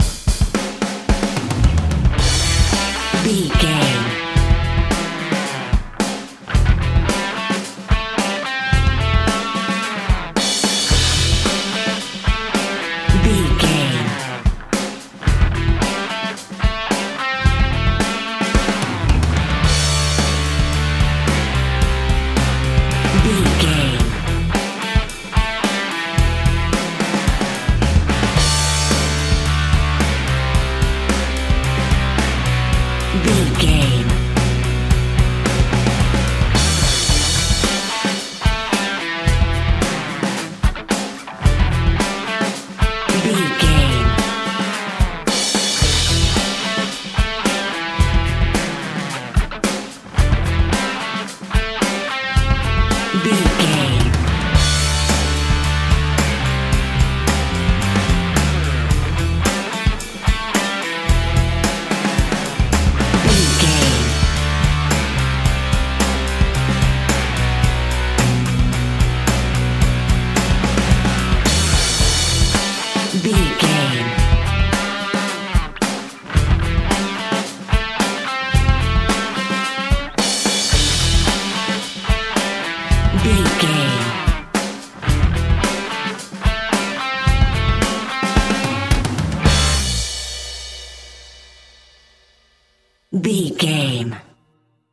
Epic / Action
Aeolian/Minor
hard rock
lead guitar
bass
drums
aggressive
energetic
intense
nu metal
alternative metal